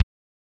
practicekick.wav